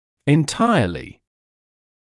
[ɪn’taɪəlɪ][ин’тайэли]полностью, всецело